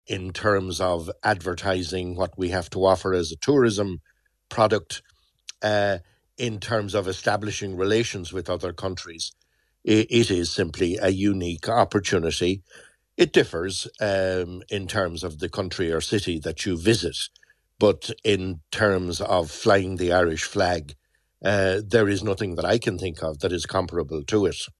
Former Minister Pat Rabbitte says while the cynicism around the visits is inevitable, he says they are a unique opportunity for Ireland: